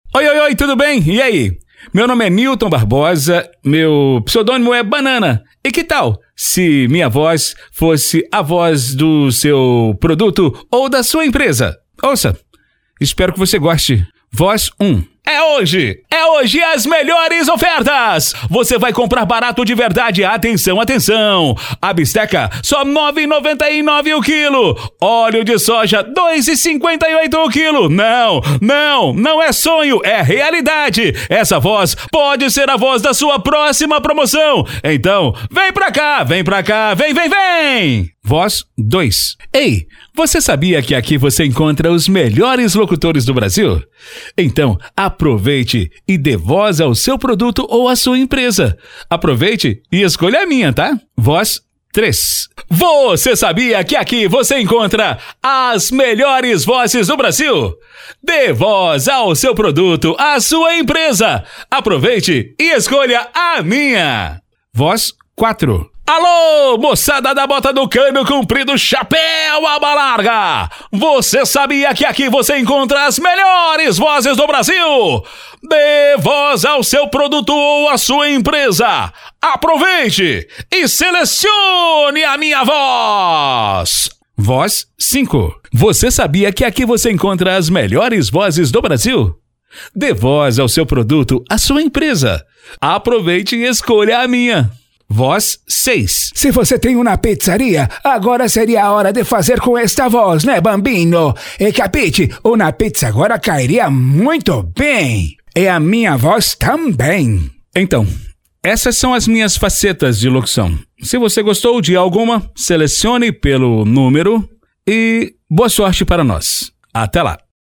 Spot Comercial
Vinhetas
Padrão
Animada
Caricata